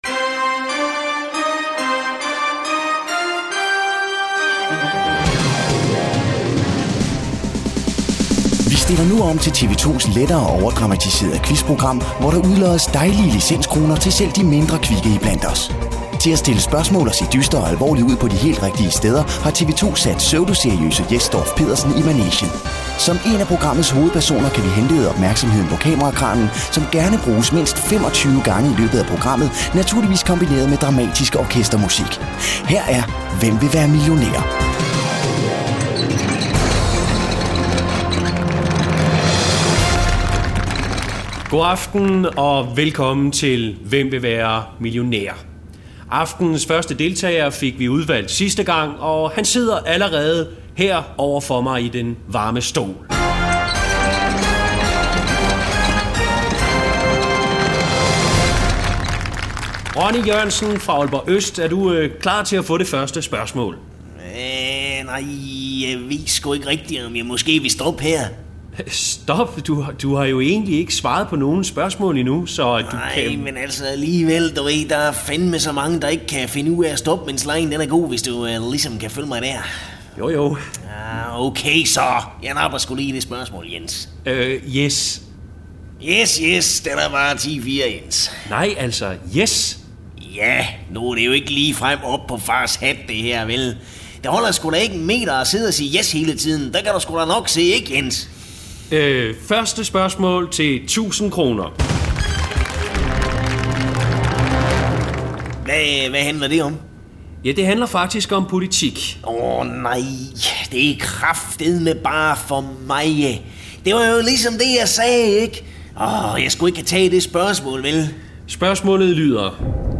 Det er her, du kan høre alle de gode, gamle indslag fra ANR's legendariske satireprogram.
For anden gang i Farlig Fredags historie blev redaktionen samlet til 3 timers "Farligt Nytår" nytårsaftensdag.